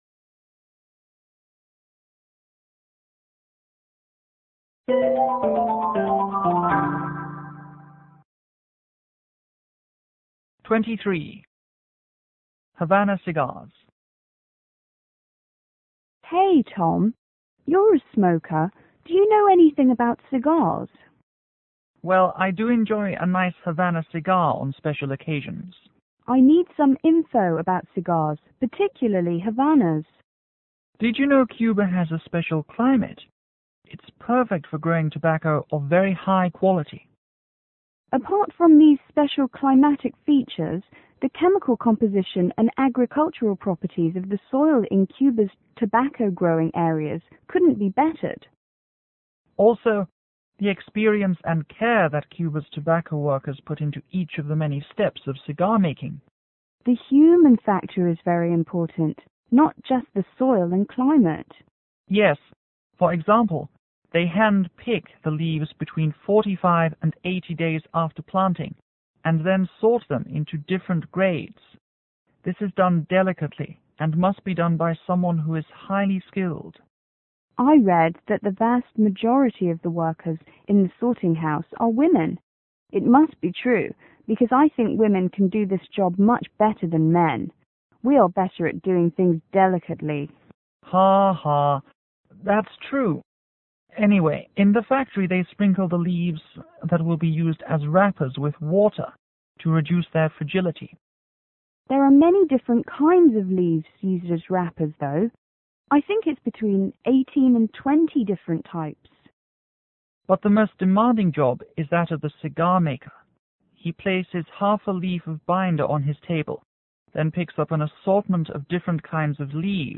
M: Man    W: Woman